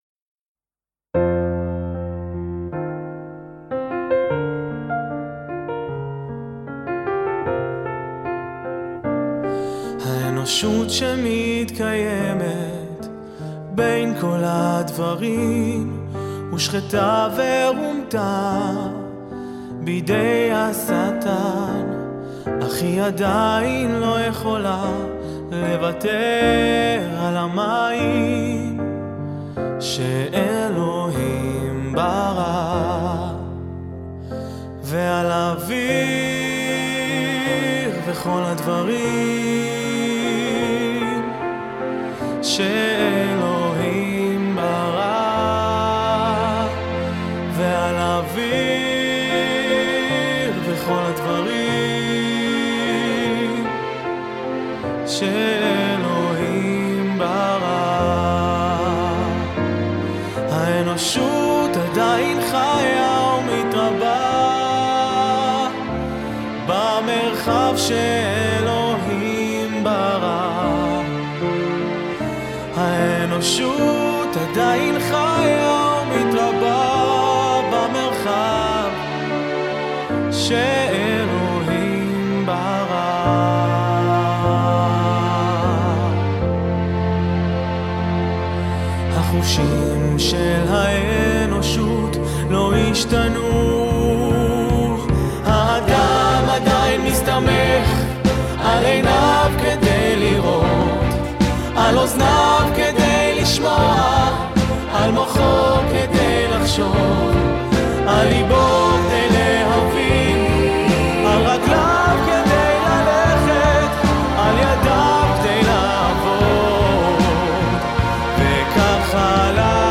Categories: Hymns of God's Words